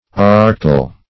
archaical - definition of archaical - synonyms, pronunciation, spelling from Free Dictionary Search Result for " archaical" : The Collaborative International Dictionary of English v.0.48: Archaical \Ar*cha"ic*al\, a. Archaic.